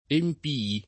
emp&-i], ecc.; meno com. empiei [